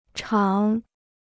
厂 (chǎng): factory; yard.